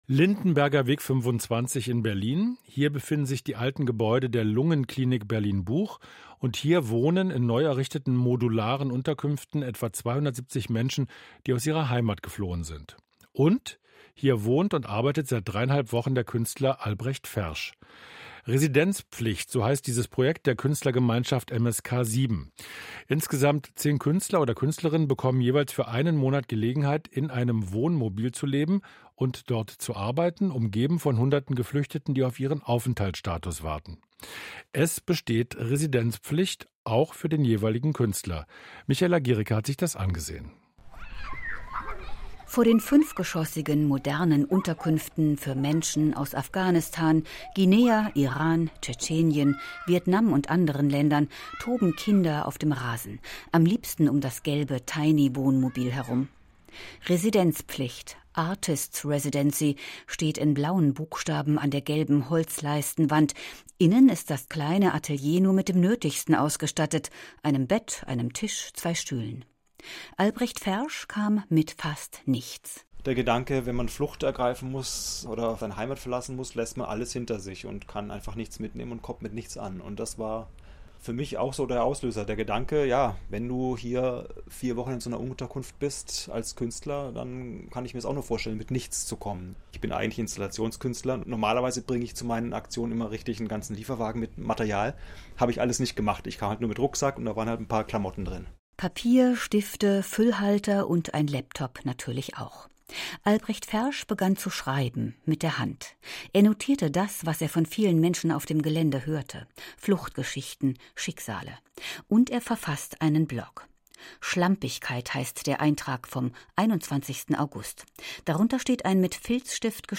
Radiobeitrag